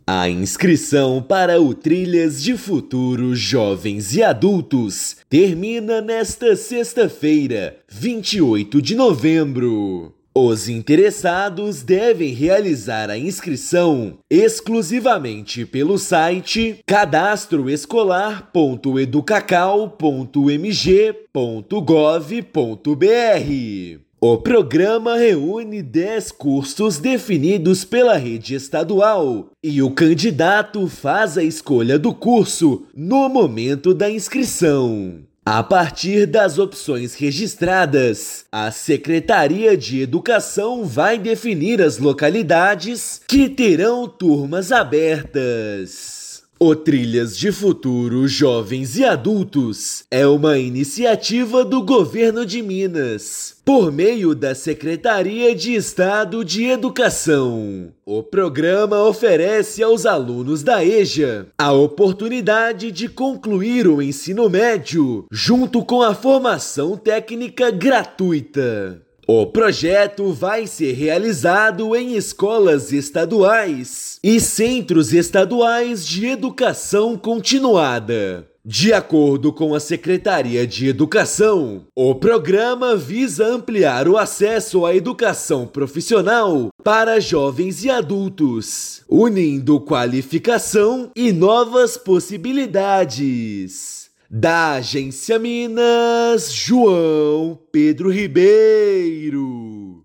[RÁDIO] Prazo para inscrições no primeiro período de oferta do Trilhas Jovens e Adultos termina nesta sexta-feira (28/11)
Oportunidades incluem cursos técnicos gratuitos e formação integrada ao ensino médio para jovens e adultos. Ouça matéria de rádio.